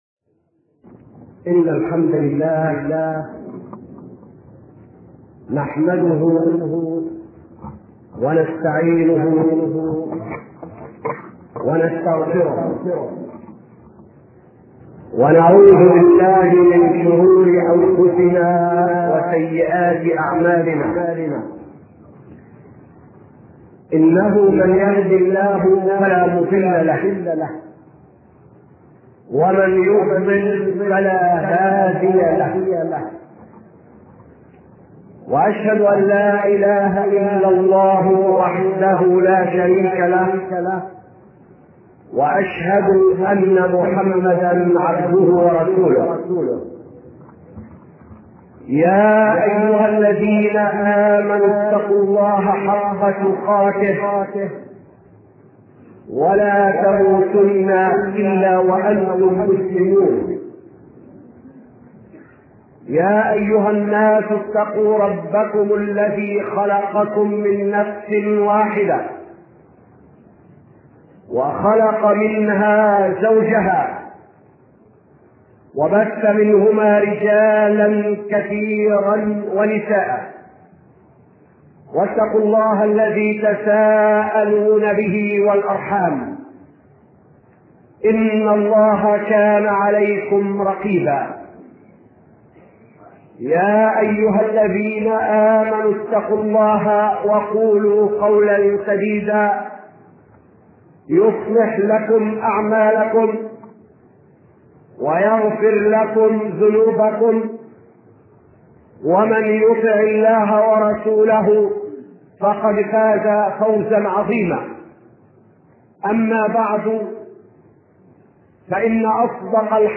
أرشيف صوتي لدروس وخطب ومحاضرات